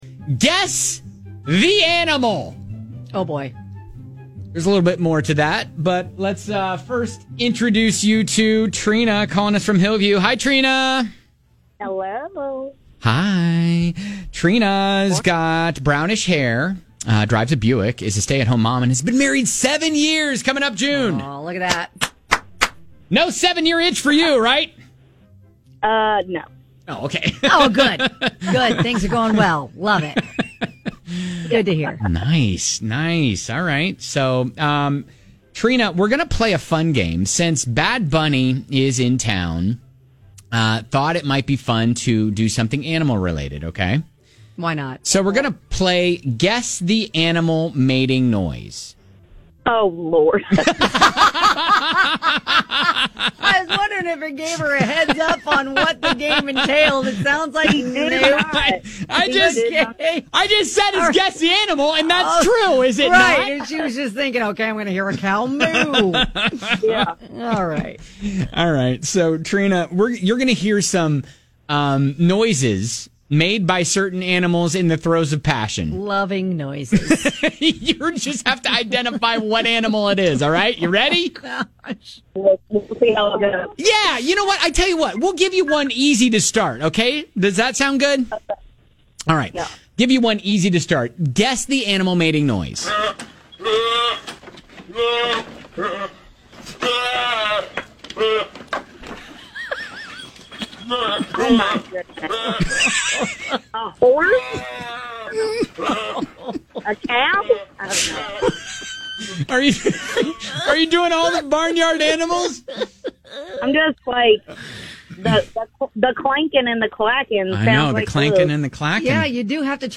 We'll play you a sound and you just have to guess the animal that's "in love" lol!